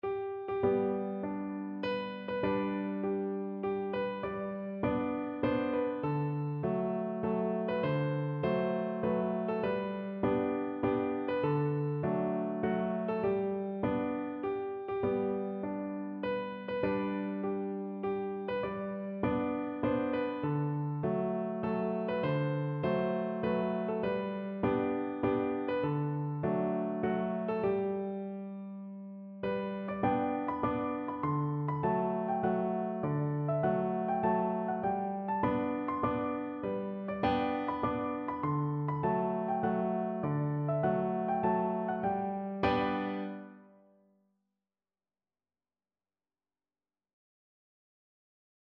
No parts available for this pieces as it is for solo piano.
Moderato
3/4 (View more 3/4 Music)
Piano  (View more Easy Piano Music)
Traditional (View more Traditional Piano Music)